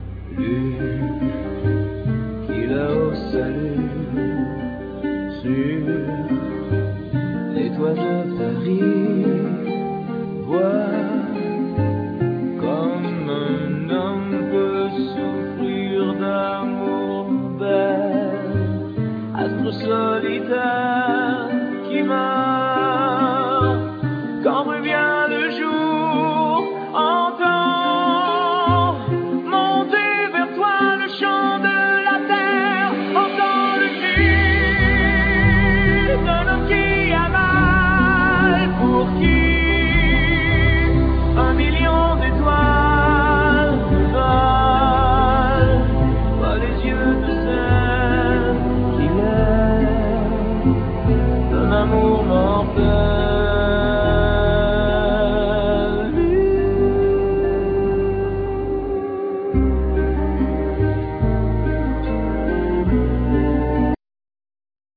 Keyboards
Bass
Guitar
Drums
Percussions